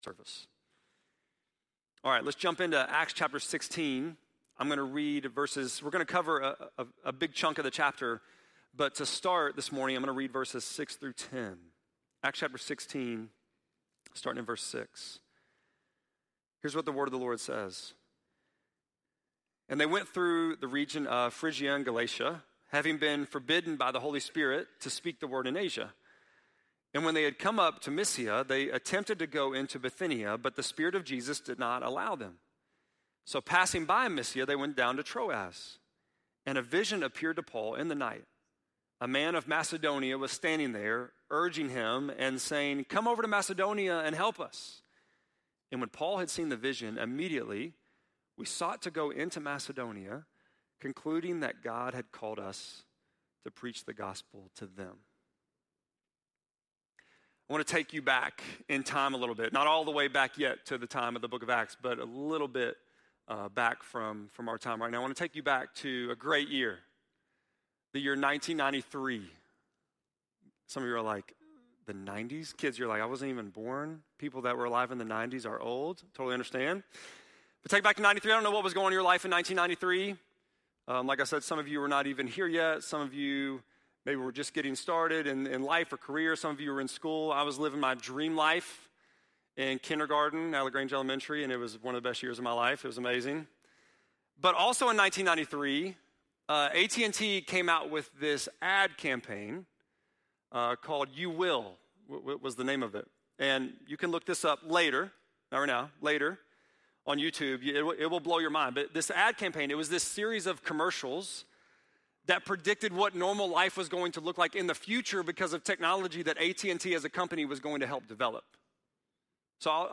9.15-sermon.mp3